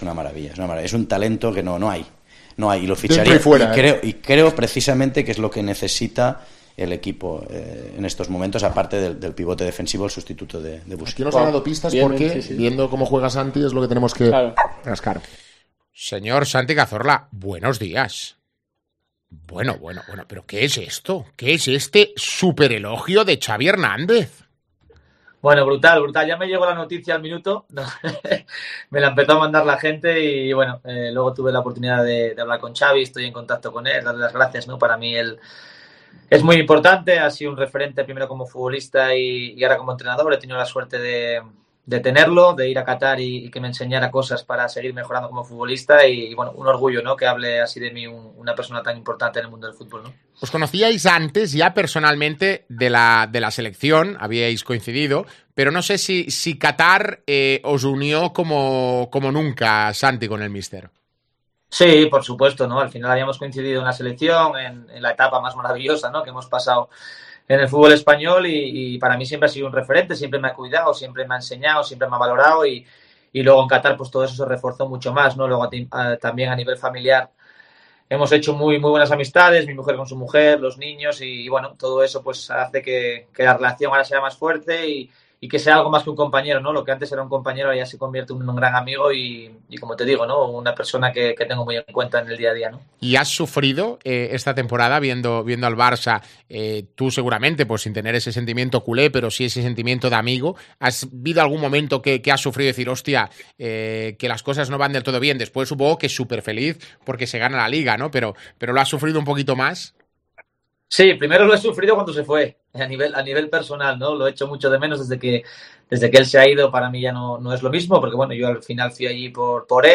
En una entrevista con Gerard Romero en Jijantes el asturiano asegura que sigue con ilusión por competir.